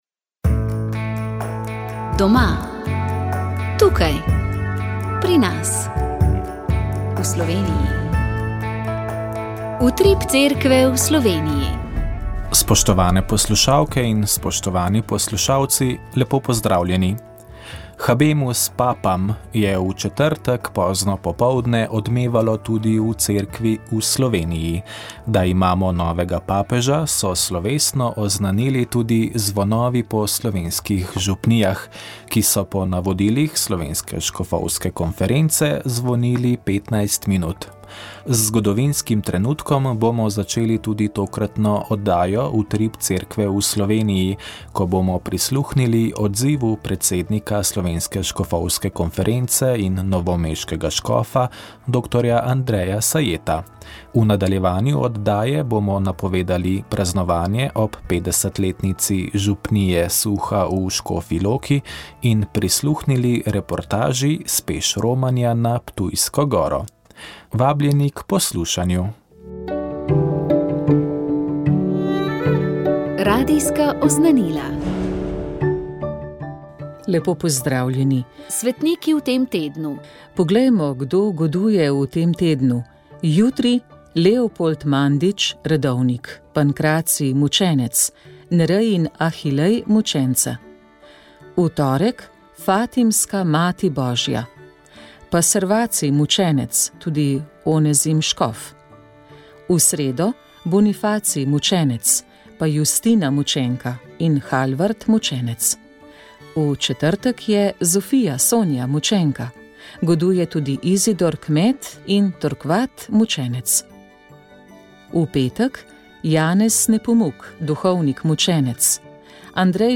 Sveta maša
Sv. maša iz stolne cerkve sv. Janeza Krstnika v Mariboru 18. 4.
Maševal je nadškof Alojzij Cvikl. Peli so bogoslovci.